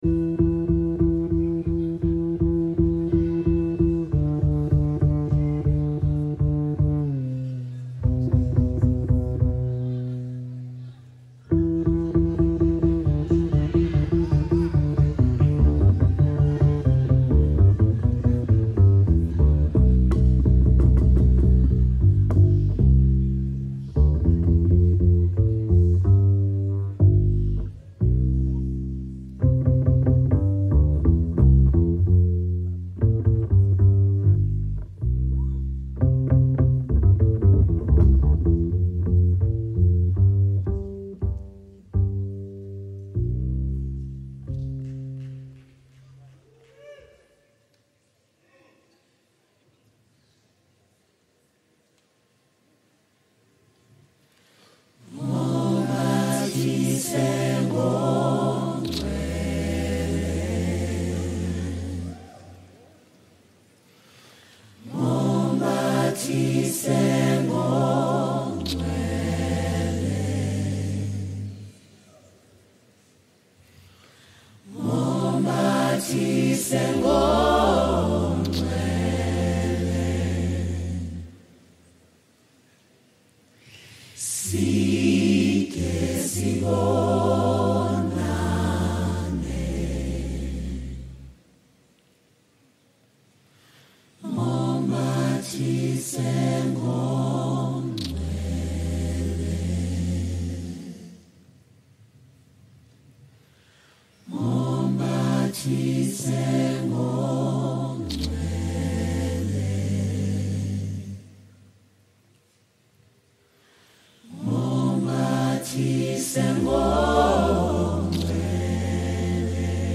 South African singer-songsmith